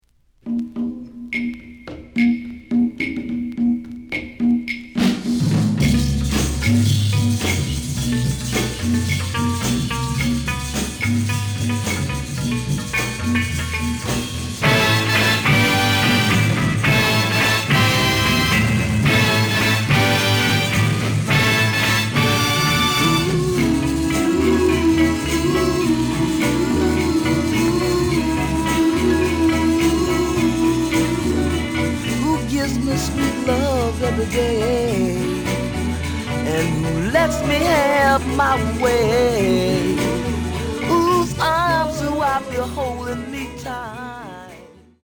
The listen sample is recorded from the actual item.
●Genre: Soul, 60's Soul
●Record Grading: VG (傷は多いが、プレイはまずまず。Plays good.)